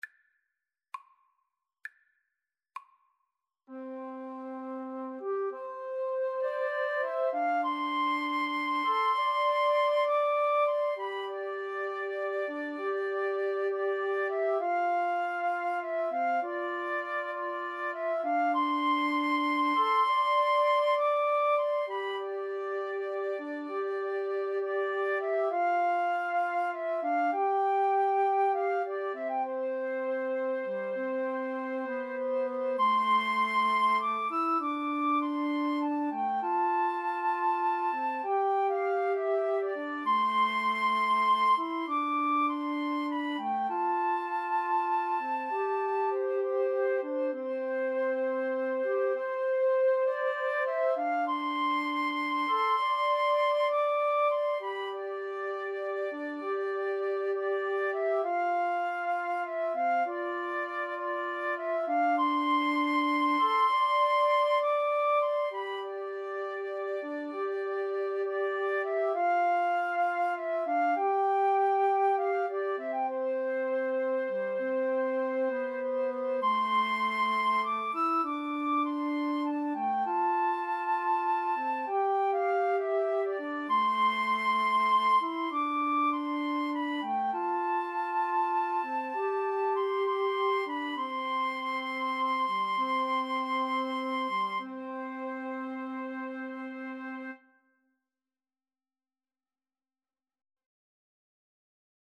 6/8 (View more 6/8 Music)
Classical (View more Classical Flute Trio Music)